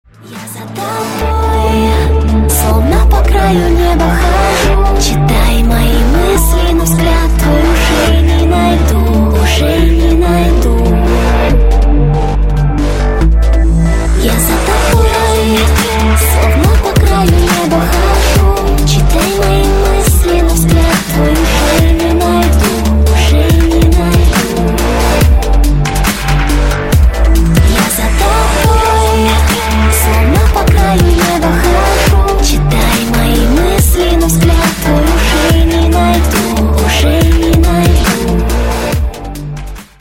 громкие
дуэт
Melodic dubstep
красивый женский голос
Vocal dubstep